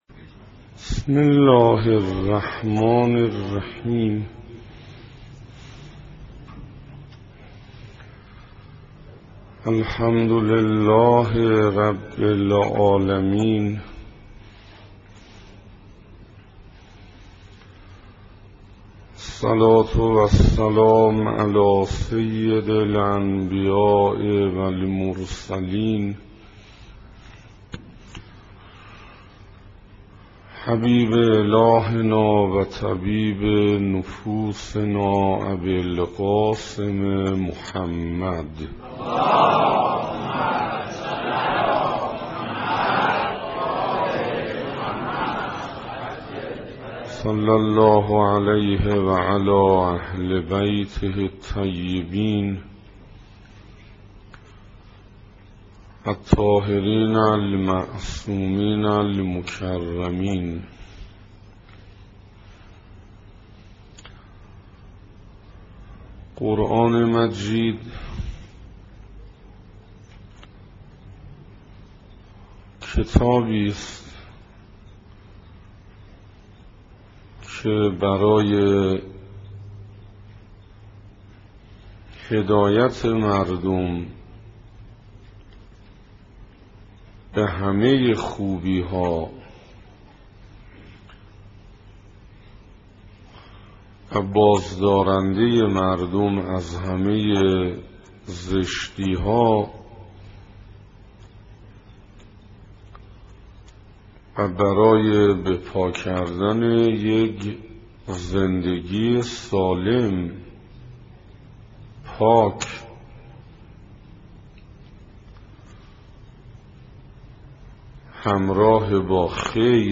سخنراني بيست و سوم
صفحه اصلی فهرست سخنرانی ها نگاهي به آيات قرآن (2) سخنراني بيست و سوم (تهران بیت الزهرا (س)) رمضان1427 ه.ق - مهر1385 ه.ش دانلود متاسفم..